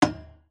Index of /files/sounds/material/metal/hit/